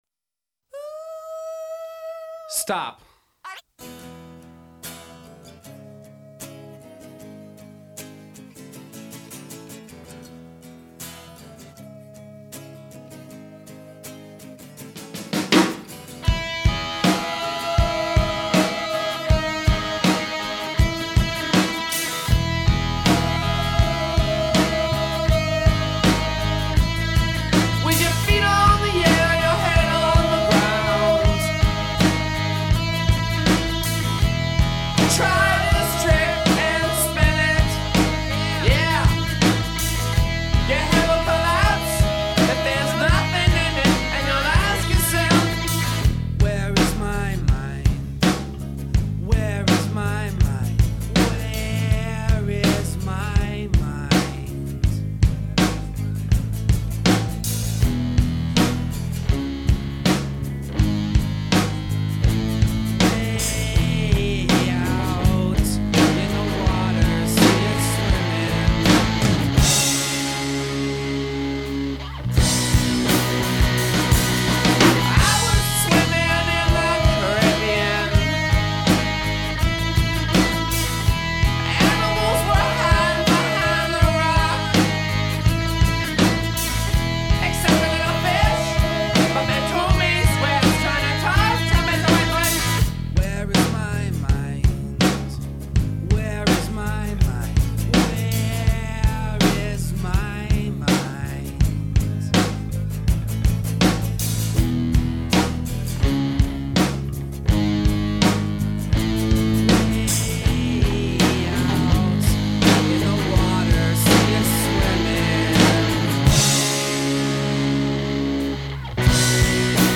Recorded this one earlier this morning.